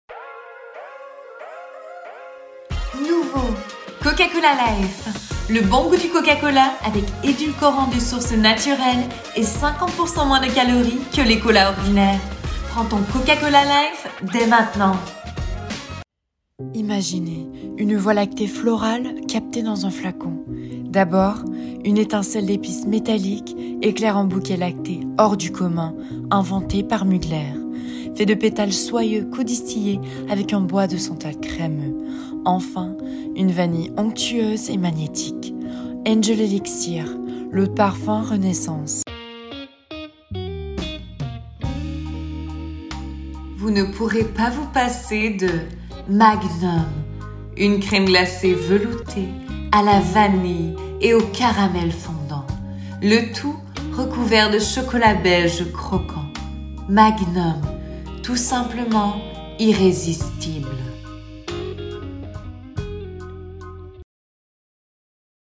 Démo Francais parisien
Voix off
20 - 40 ans - Mezzo-soprano